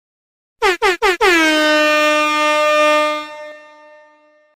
Buzina DJ